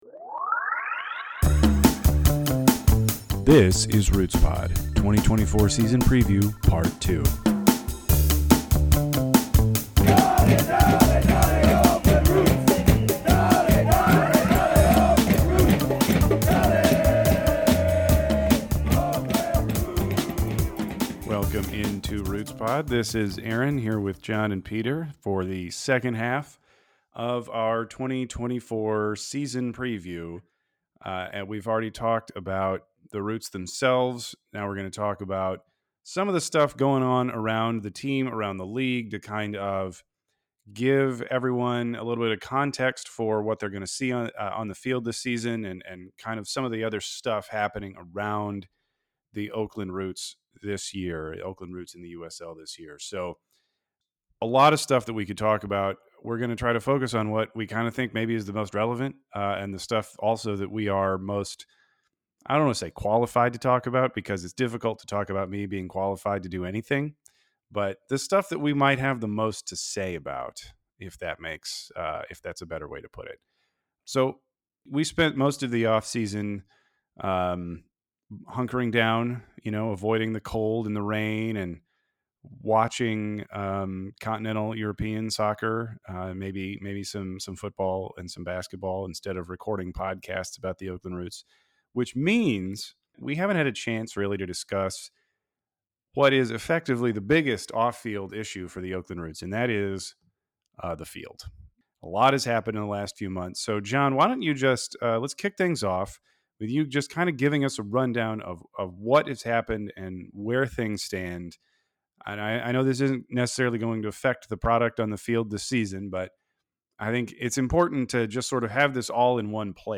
challenges himself to see how far away from his mic he can sit and still record the pod